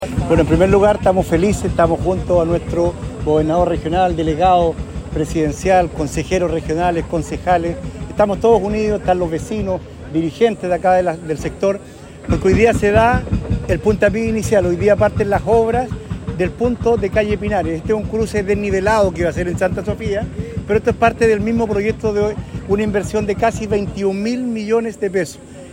El inicio de obras contó con la presencia de autoridades regionales, quienes concordaron en destacar la importancia de la iniciativa que busca mejorar la conectividad urbana de la comuna, así como también potenciar la seguridad vial en el entorno de la nueva infraestructura.
Por su parte, el alcalde de la comuna de Chiguayante, Jorge Lozano, se mostró feliz por este hito.